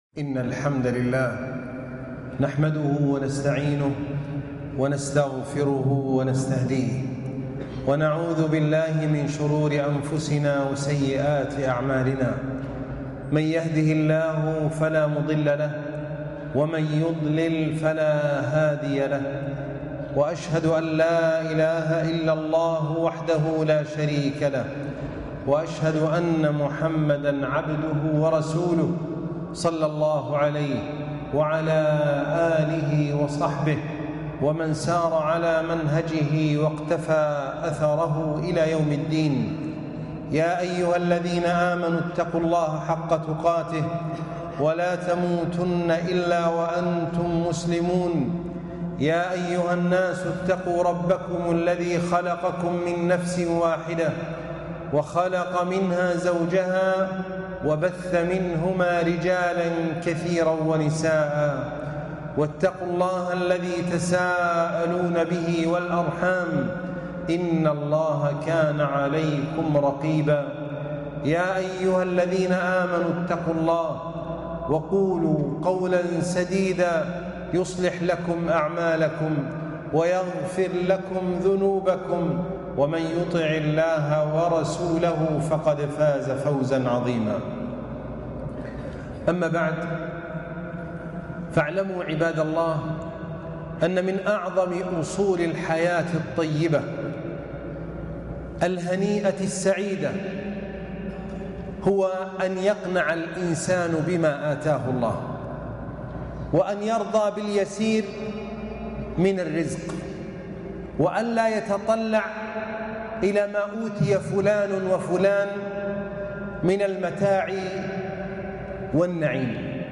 الكنز الذي لا ينفد - خطبة الجمعة